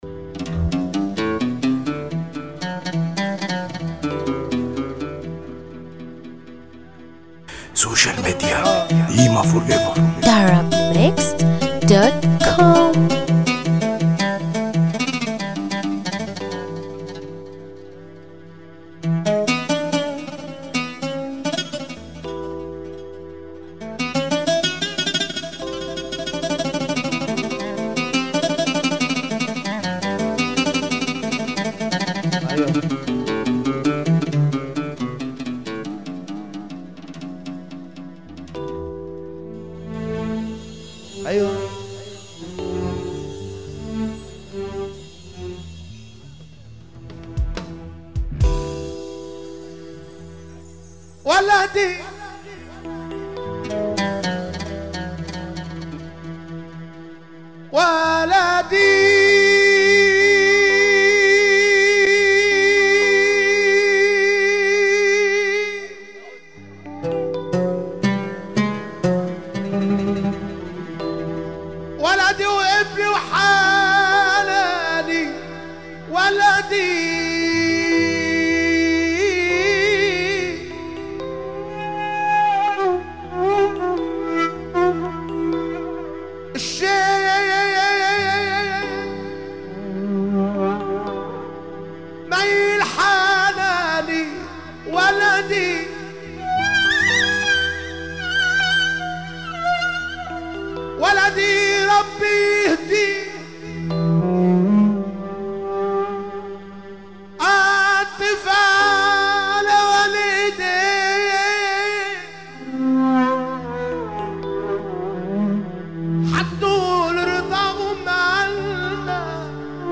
موال
حزينة جدا مع اقوى الطلعات